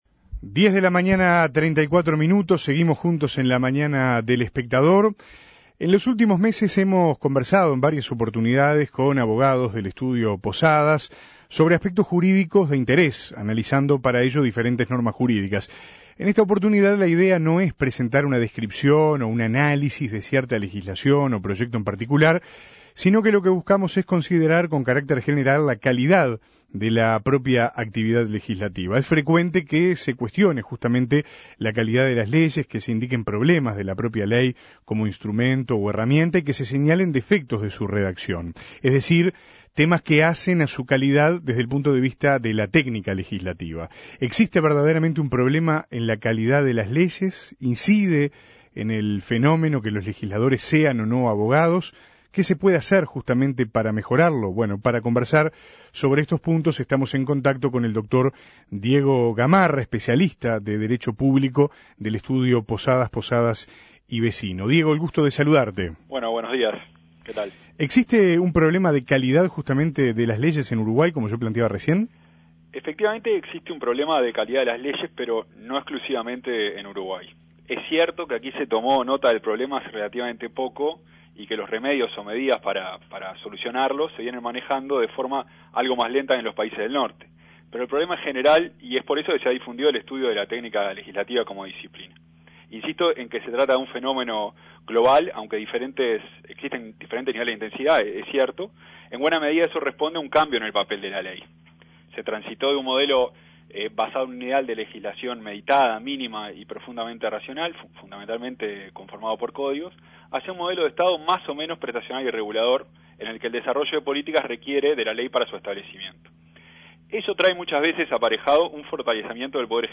Análisis Posadas, Posadas y Vecino